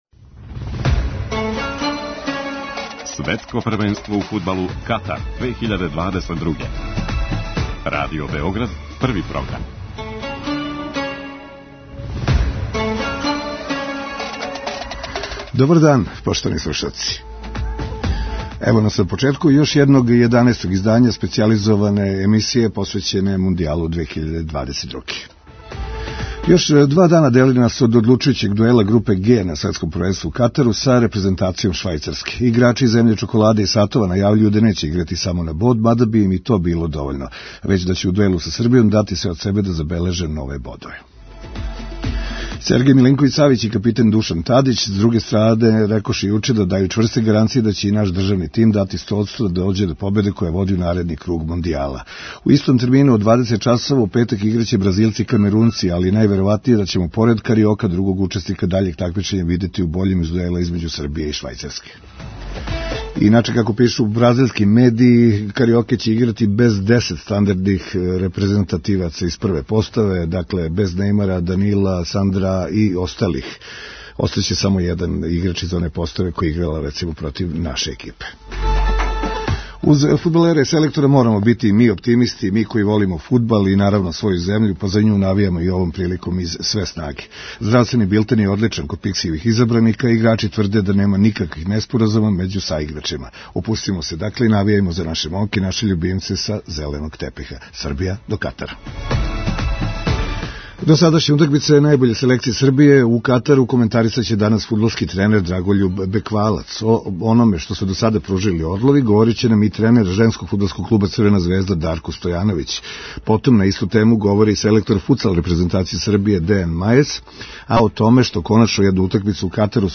Чућемо и изјаве са данашње конференције за медије наше репрезентације, као и низ занимљивости из Катара, укључујући и несвакидашње трке камила које су обогатиле понуду гостима Мондијала 2022.